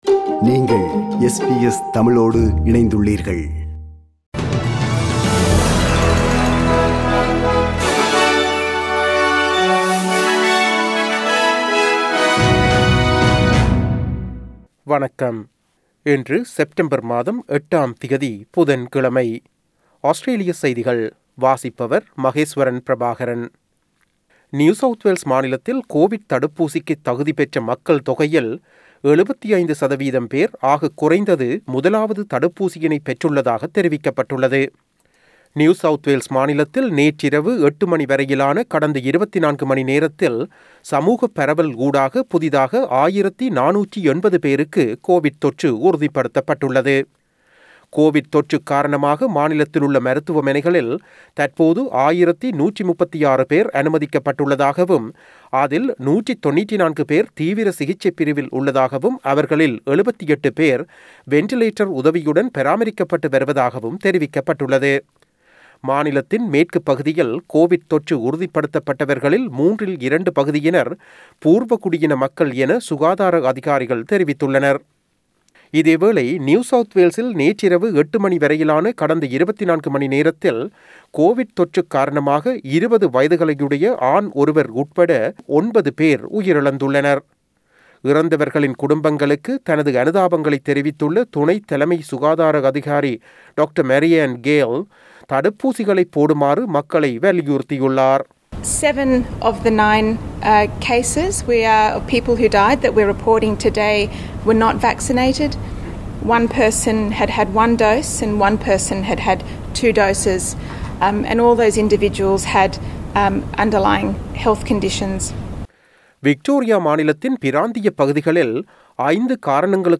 Australian news bulletin for Wednesday 08 September 2021.